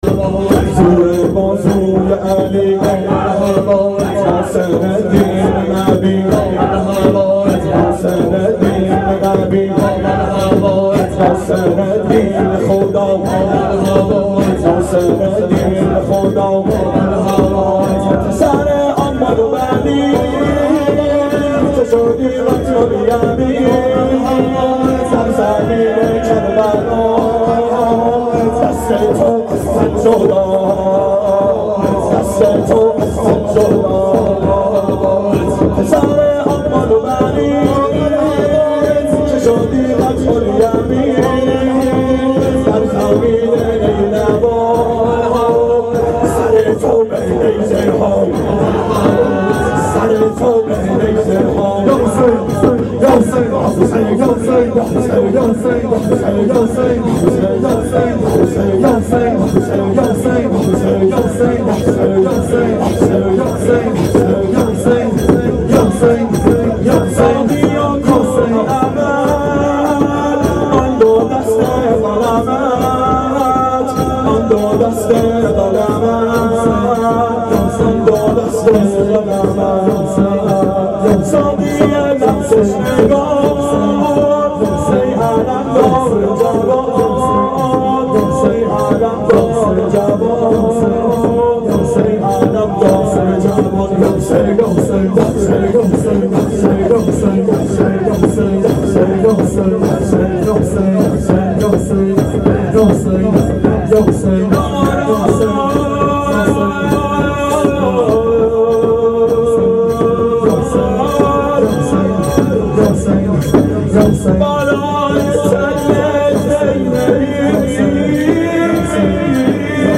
• شب عاشورا 92 هیأت عاشقان اباالفضل علیه السلام منارجنبان